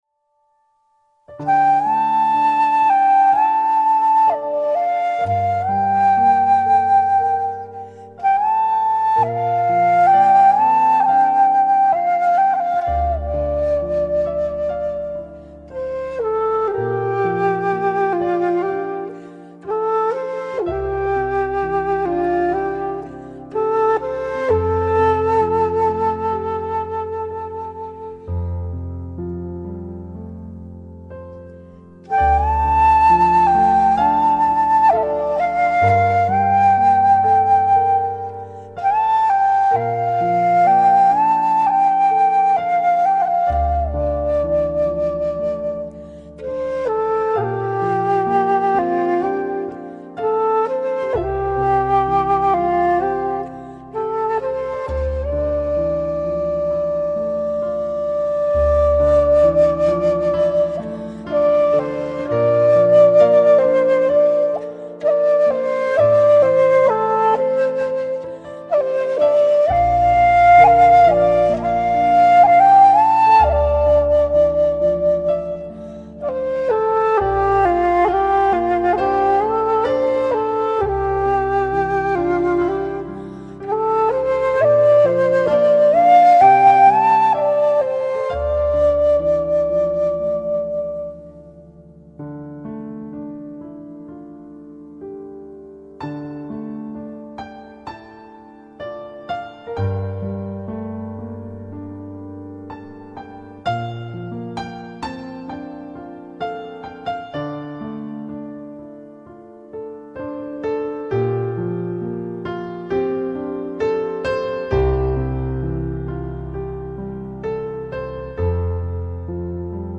Bamboo_Flute.mp3